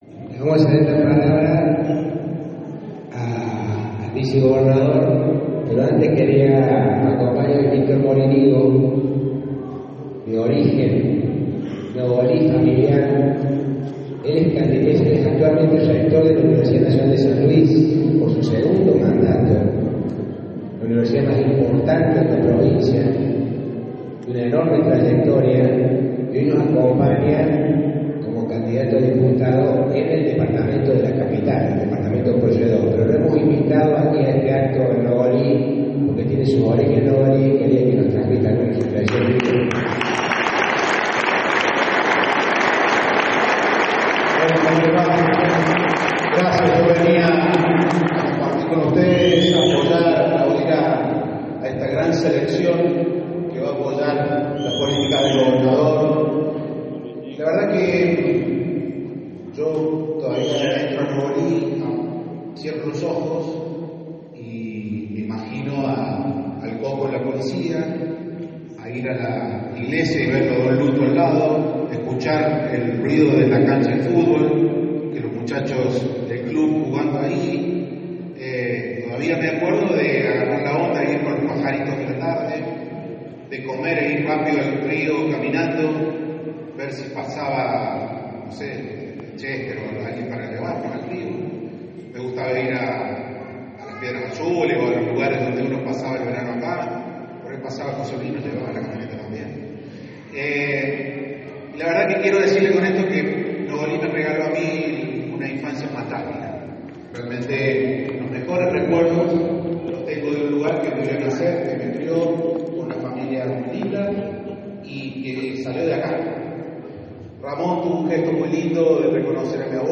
Este martes por la noche en el Club Defensores de Nogolí, Víctor Moriñigo participó junto al Gobernador Claudio Poggi de la presentación de los candidatos del Frente Ahora San Luis.